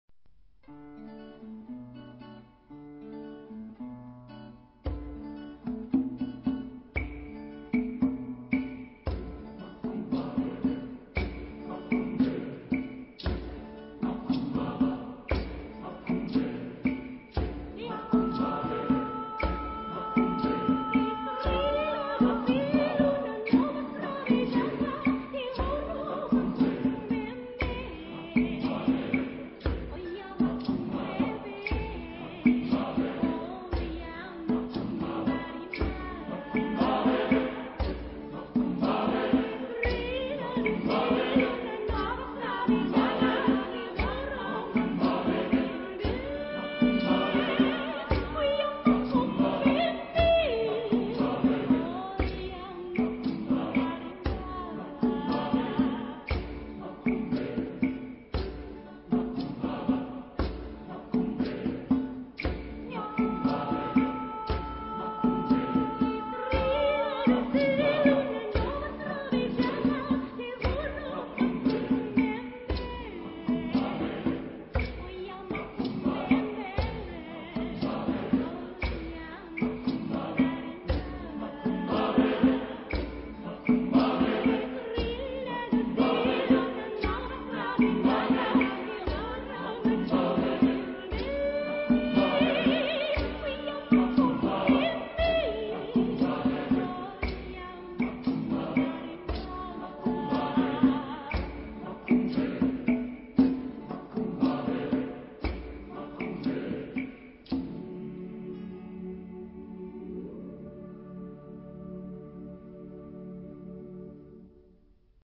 Genre-Style-Forme : Profane ; Populaire ; Latino-américain
Caractère de la pièce : vivant ; rythmé ; syncopé
Type de choeur : SATTBB  (4 voix mixtes )
Tonalité : ré majeur